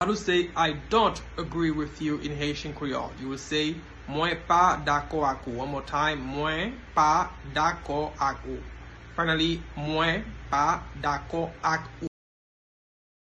Listen to and watch “Mwen pa dakò ak ou” pronunciation in Haitian Creole by a native Haitian  in the video below:
I-dont-agree-with-you-in-Haitian-Creole-Mwen-pa-dako-ak-ou-pronunciation-by-a-Haitian.mp3